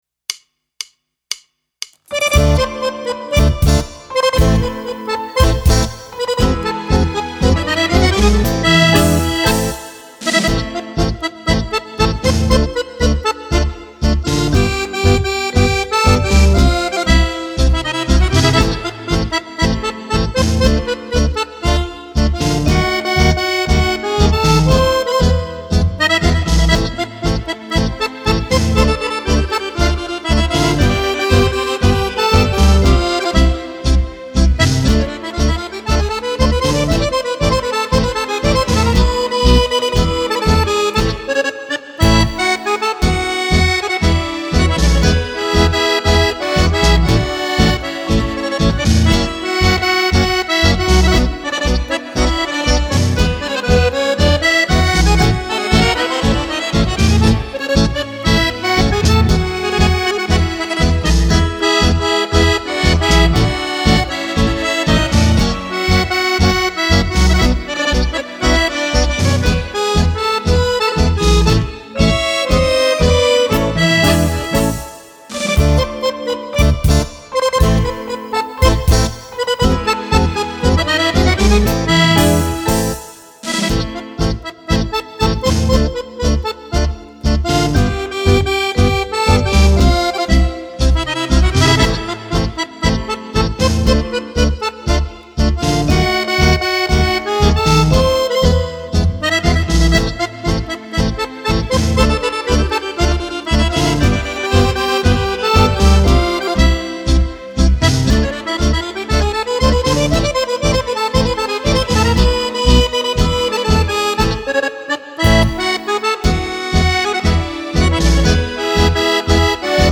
Tango
Tango per Fisarmonica